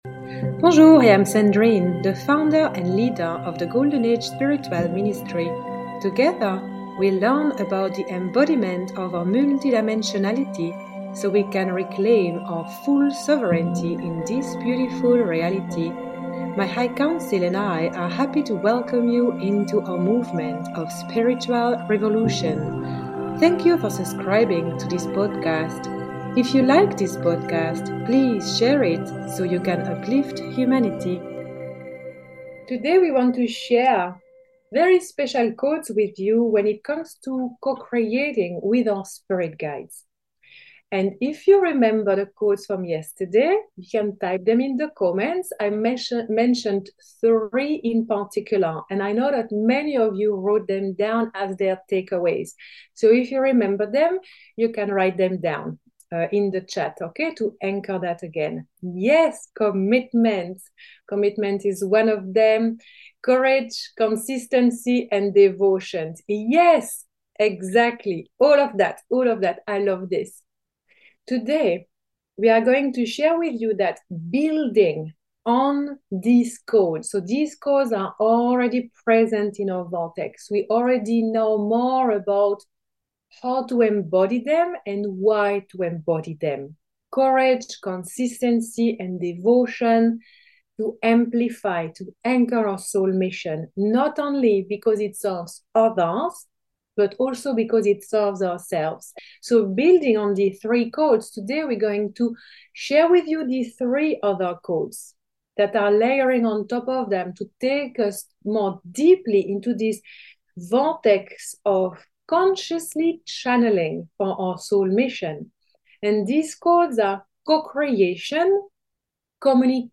This transmission is a clip taken from my free 4-day channeling challenge to amplify your soul mission.